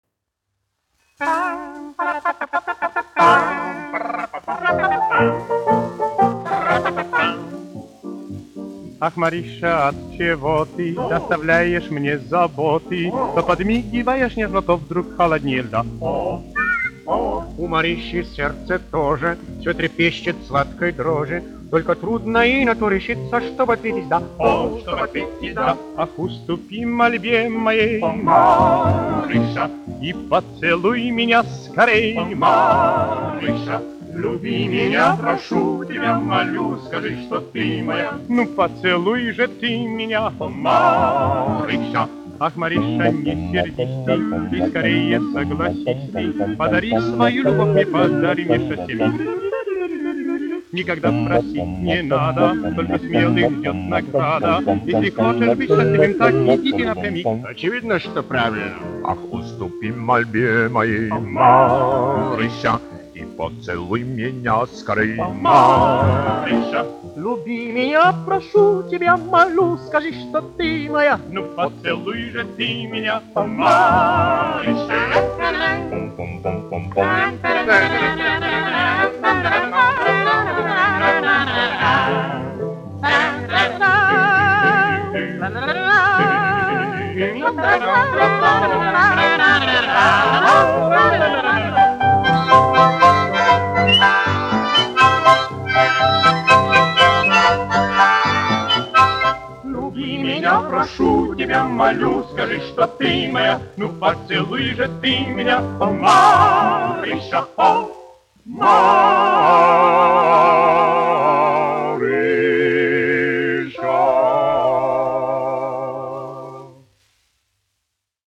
1 skpl. : analogs, 78 apgr/min, mono ; 25 cm
Fokstroti
Vokālie ansambļi ar klavierēm